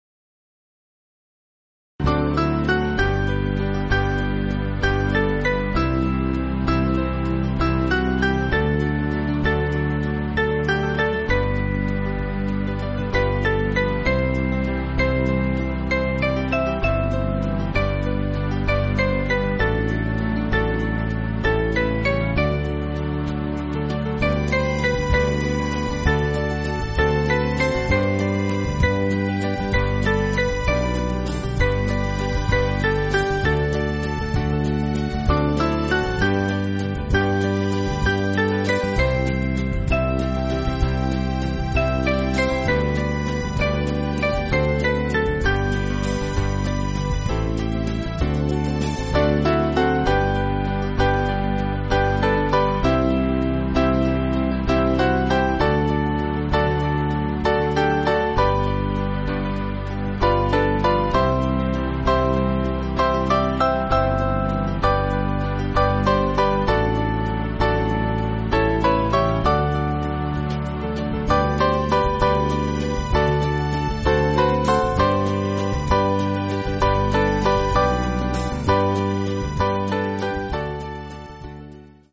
Small Band
(CM)   4/G